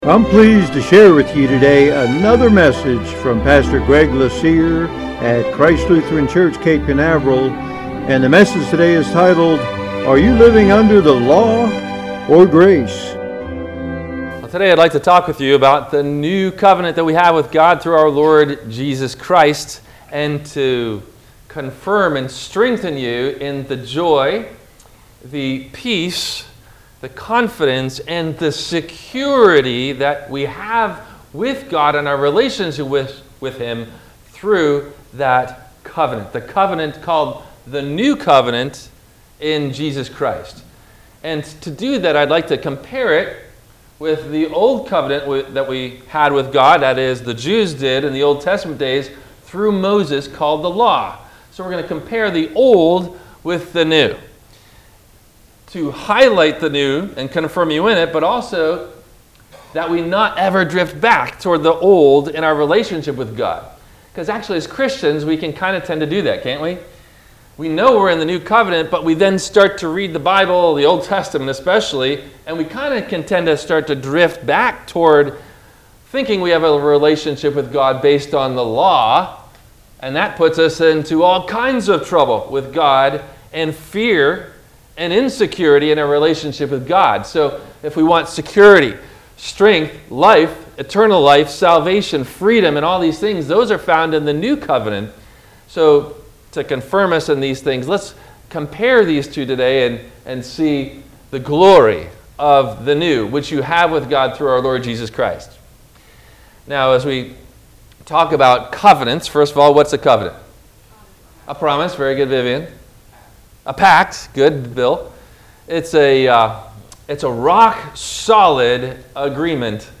Are You Living Under The Law or Grace? – WMIE Radio Sermon – August 09 2021 - Christ Lutheran Cape Canaveral